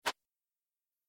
دانلود آهنگ باد 76 از افکت صوتی طبیعت و محیط
دانلود صدای باد 76 از ساعد نیوز با لینک مستقیم و کیفیت بالا
جلوه های صوتی